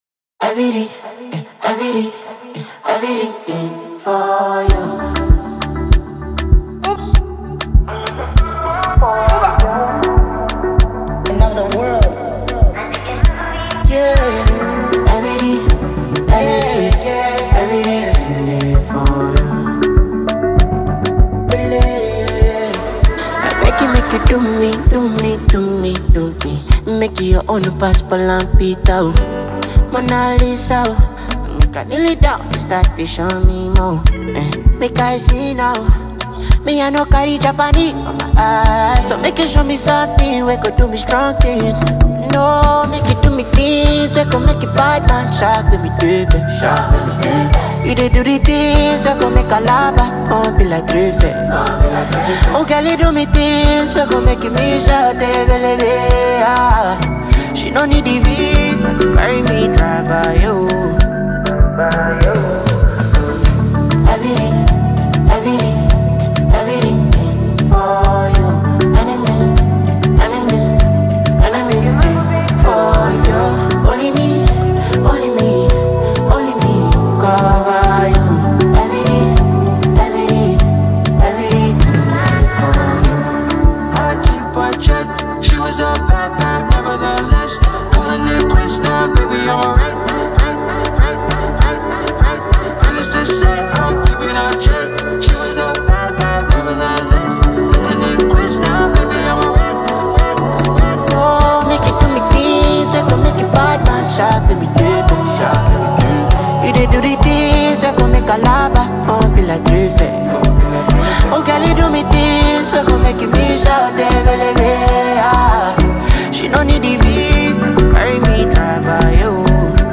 blends elements of Afro-pop and Afrobeat music